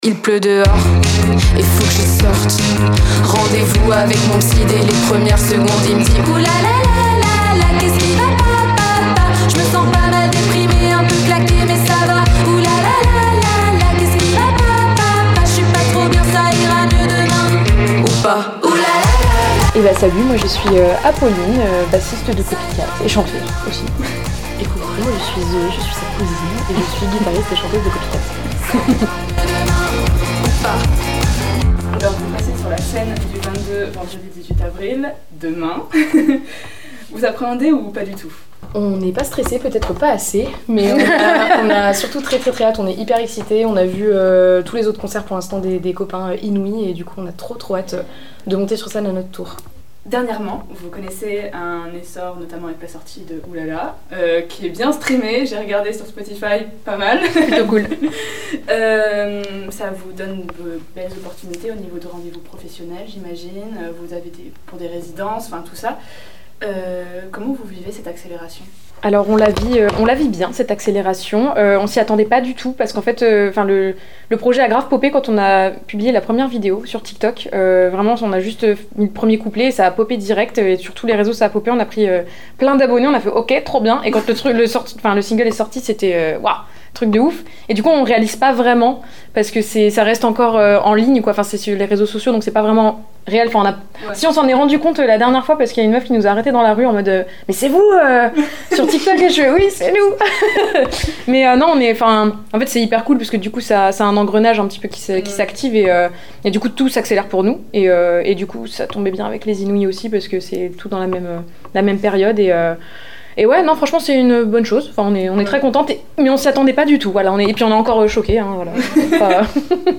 Les iNOUïS du Printemps de Bourges au Café Charbon - Copycat en direct du Printemps de Bourges en podcast - BAC FM